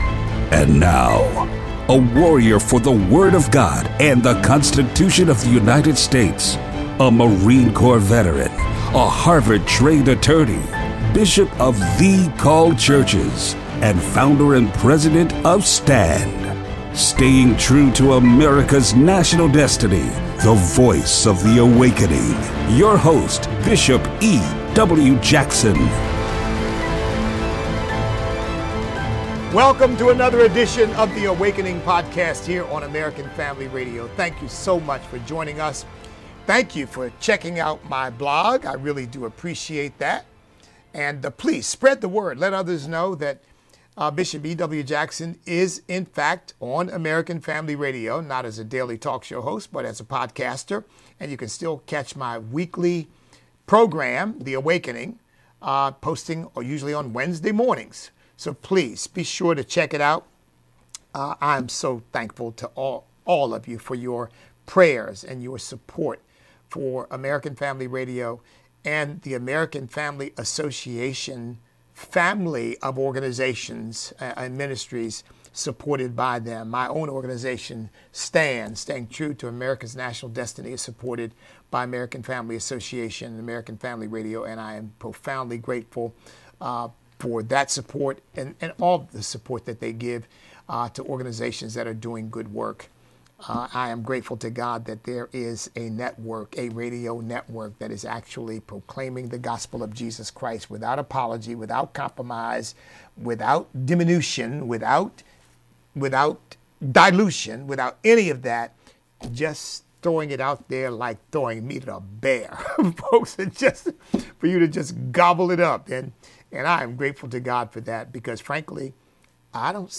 1 Vilda djur som Pia Johansson hellre matar än fåglar - På minuten Play Pause 40m ago Play Pause Play later Play later Lists Like Liked — Hans Rosenfeldt med en klocka och en pratglad panel.
Inspelat i studio 4 i Radiohuset i Stockholm den 6 oktober 2025.